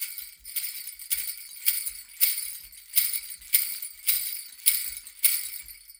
80 PERC 05.wav